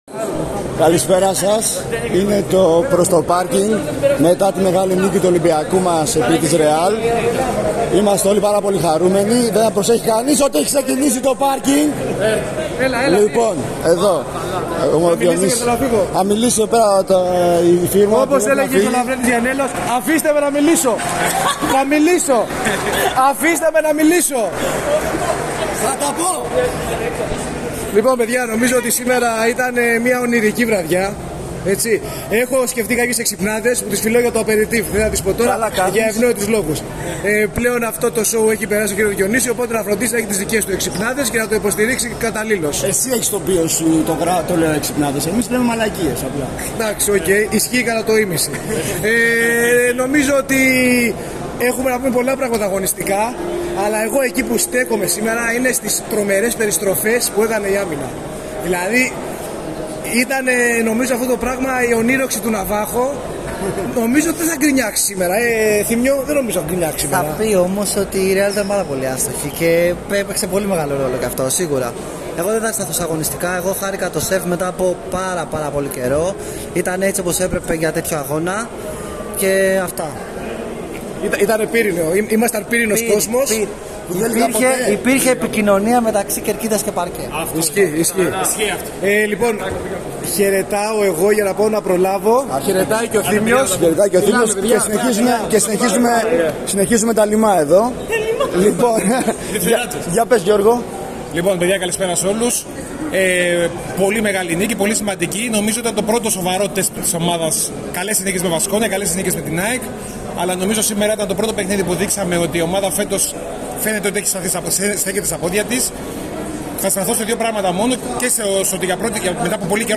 Μετά την σημαντική για την ψυχολογία της ομάδας νίκη επί της Ρεάλ, ακούστε το "Προς το Πάρκινγκ" για το πως το είδαμε αμέσως μετά από το ματς, μέσα από το ΣΕΦ.